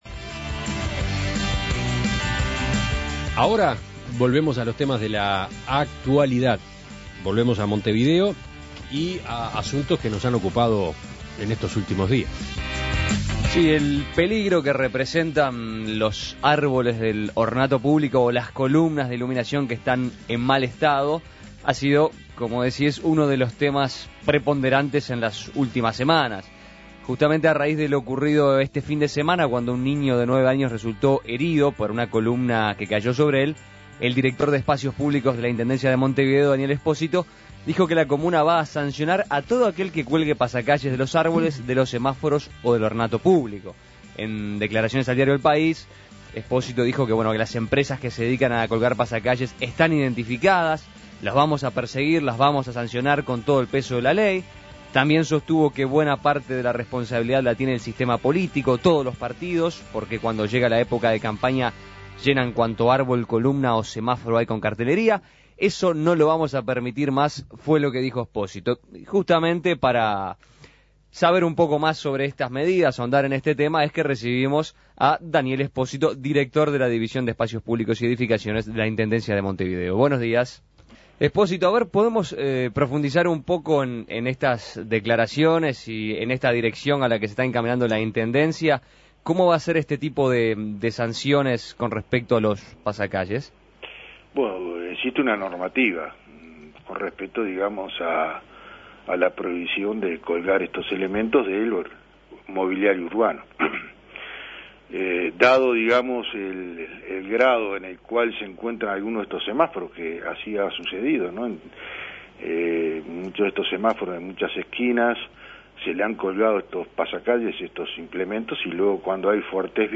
en diálogo con Daniel Espósito, director de la División Espacios Públicos y Edificaciones de la IMM.